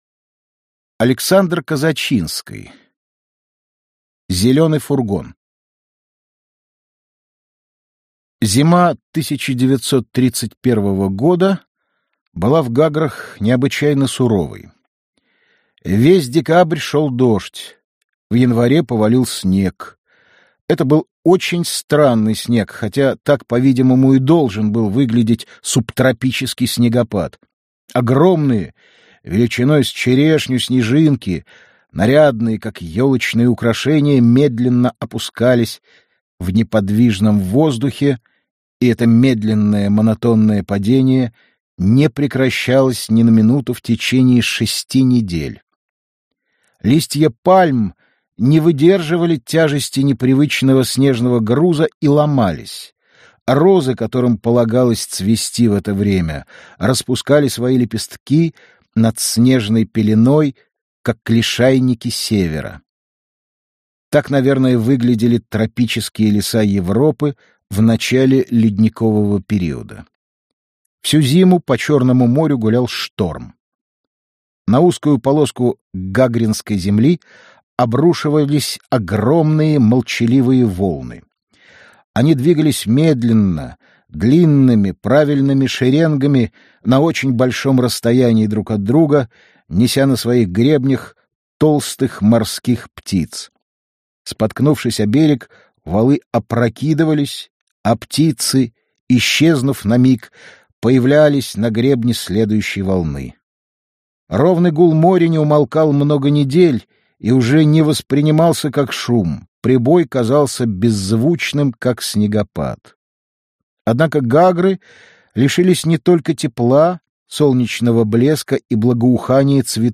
Аудиокнига Зеленый фургон - купить, скачать и слушать онлайн | КнигоПоиск